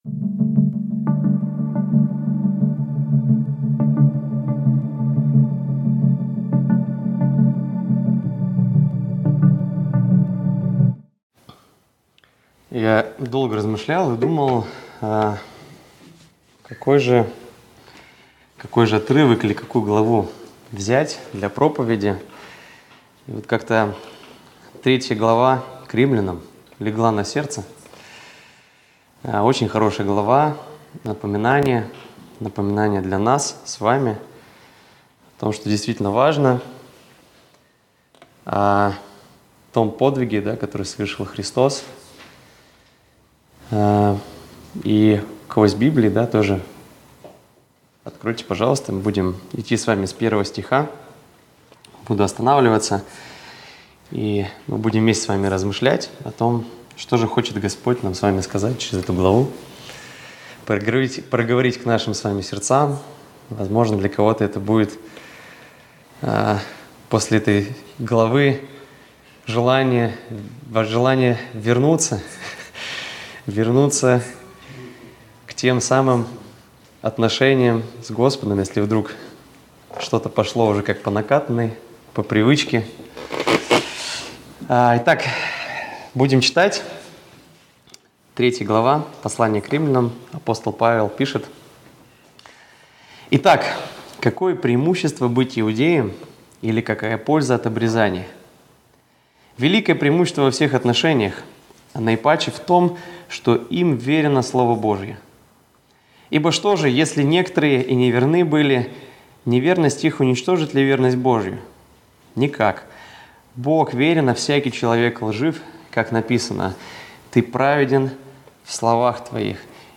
Воскресная проповедь - 2024-08-25 - Сайт церкви Преображение